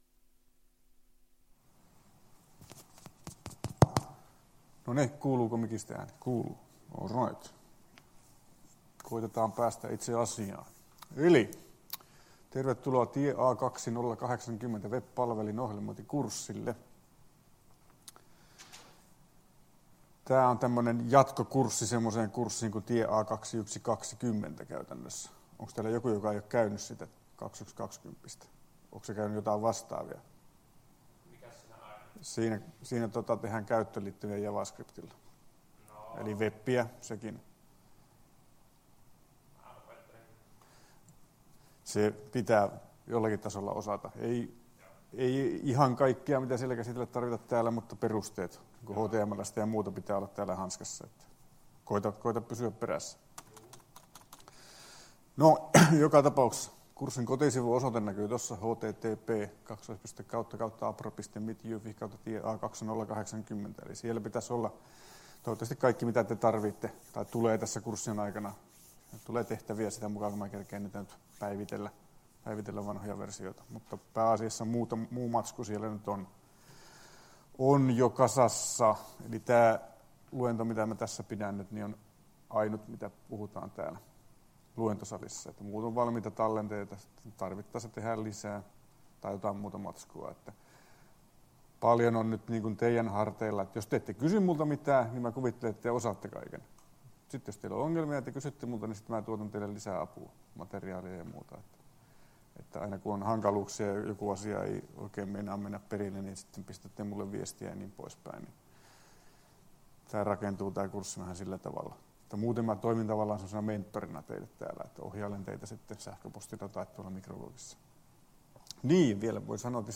Luento 07.01.2019